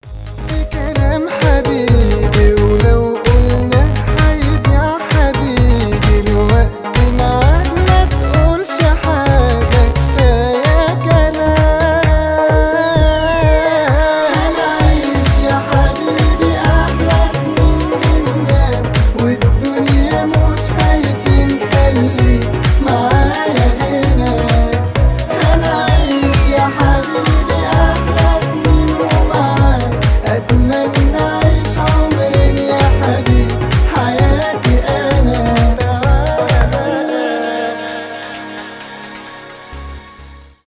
поп , клубные , арабские